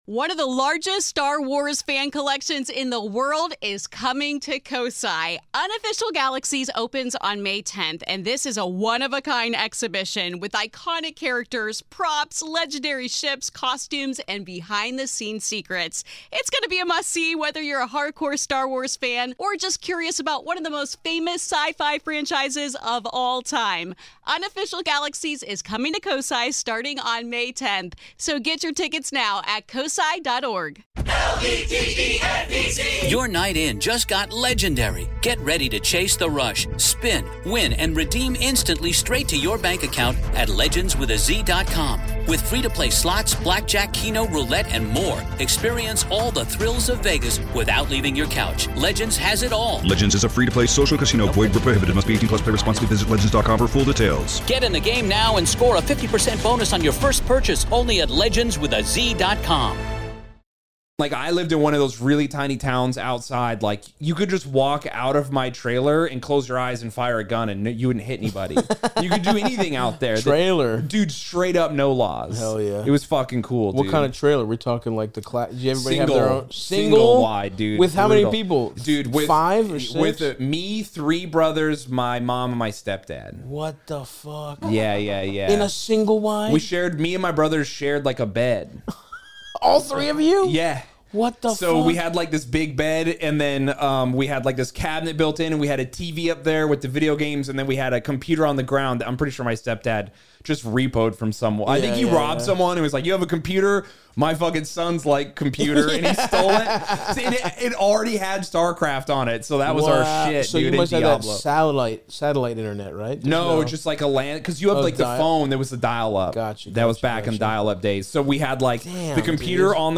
Shayne Smith joins the pod to discuss his wild upbringing, getting sober at 14, joining a biker gang, enlisting in the military, navigating life as an extremely tatted bro, and more. Shayne and Stav help callers including a fellow heavily tattooed man wondering if he should cover up when meeting his girlfriend's parents in South Korea, and a man wondering if he should come clean about accidentally killing his wife's dog.